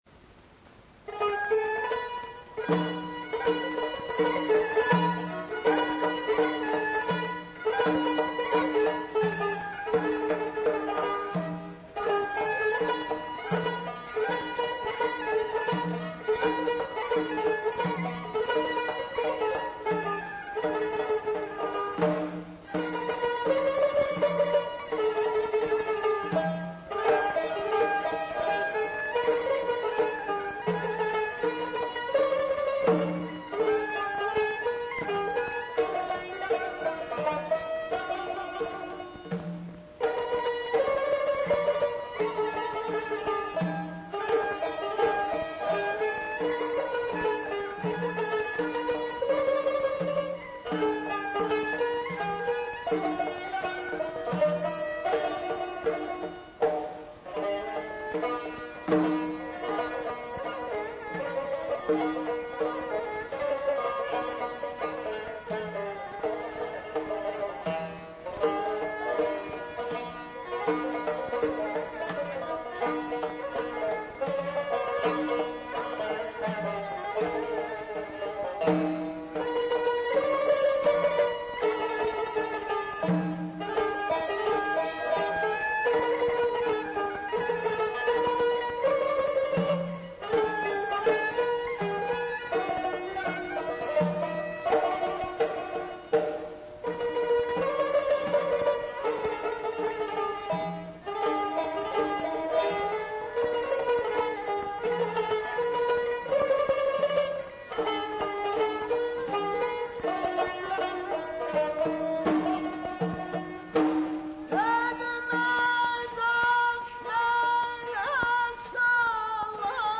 tar
kamança
dəf.- Qramval №21547.- CD №643.